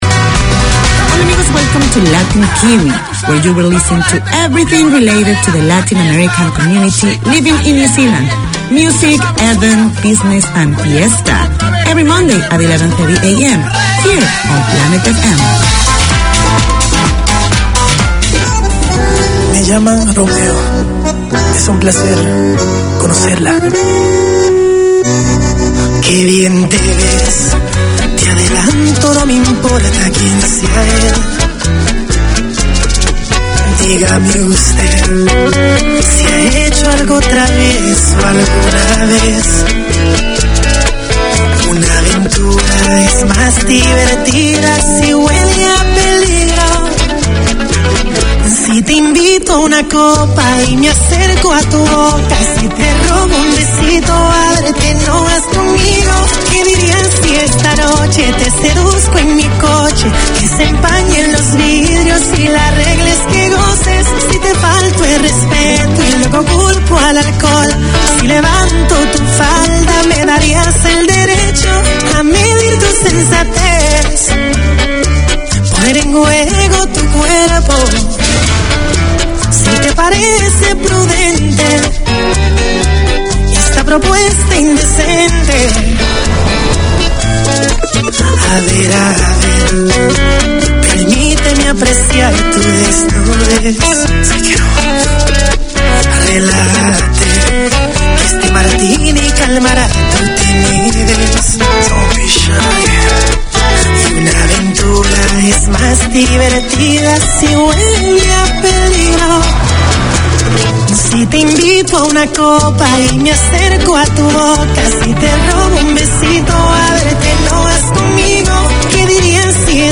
Radio made by over 100 Aucklanders addressing the diverse cultures and interests in 35 languages.
Latin Kiwi 4:25pm WEDNESDAY Community magazine Language: English Spanish Bienvenidos a todos!